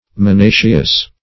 minaceous - definition of minaceous - synonyms, pronunciation, spelling from Free Dictionary
Minaceous \Min`*a"ceous\, a.